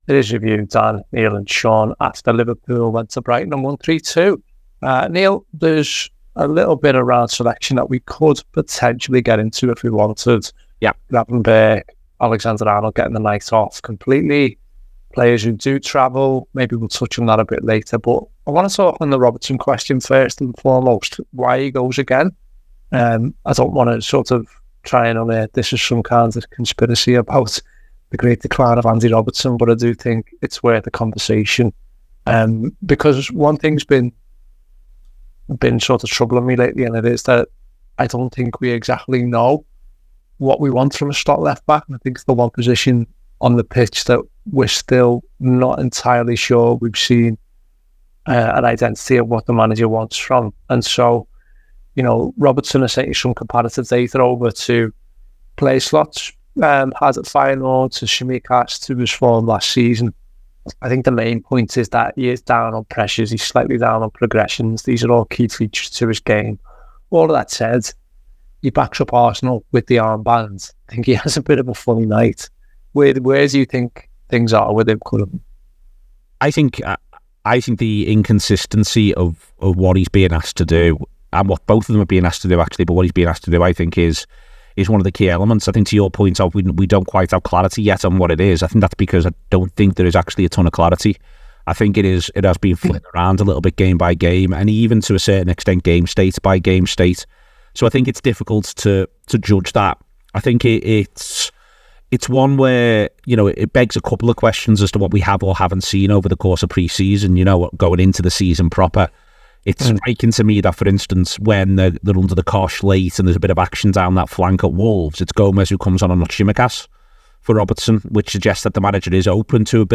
Below is a clip from the show- subscribe for more review chat around Brighton 2 Liverpool 3…